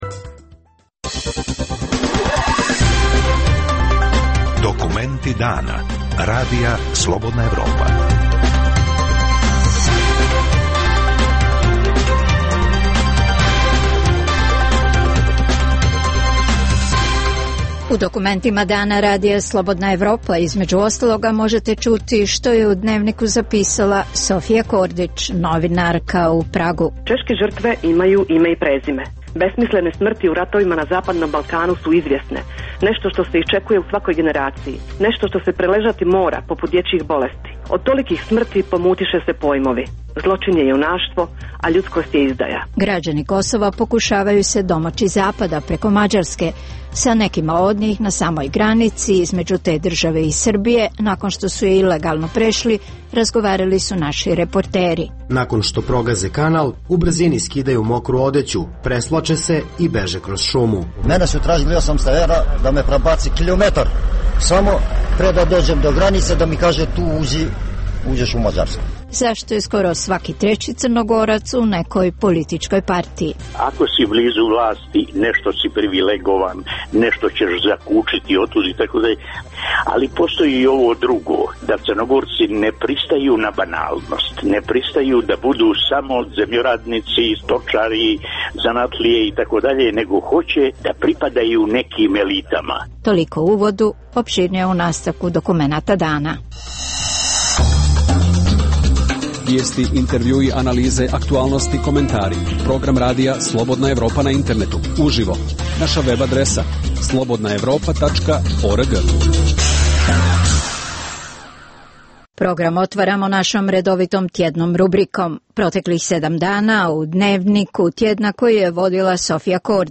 - Intervju s Ivanom Krastevom, direktorom Centra za strateške studije u Sofiji. - Reportaža s ilegalnim migrantima s Kosova. - Svaki treći Crnogorac u nekoj partiji.